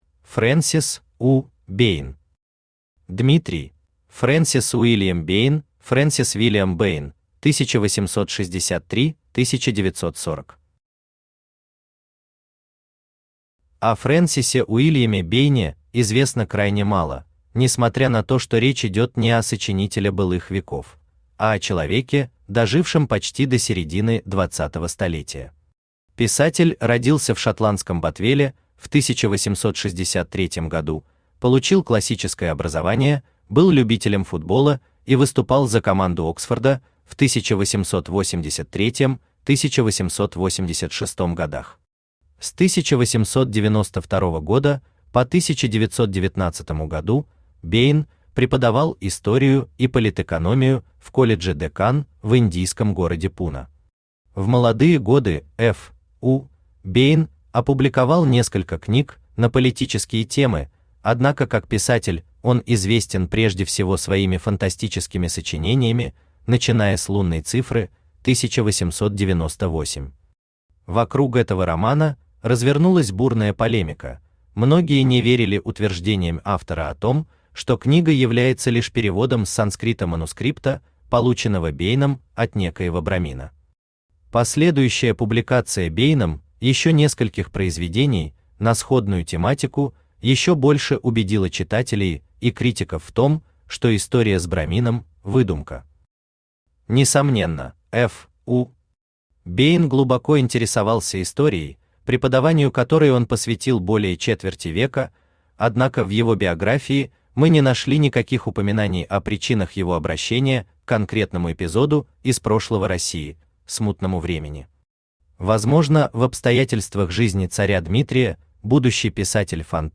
Аудиокнига Дмитрий | Библиотека аудиокниг
Aудиокнига Дмитрий Автор Фрэнсис У. Бейн Читает аудиокнигу Авточтец ЛитРес.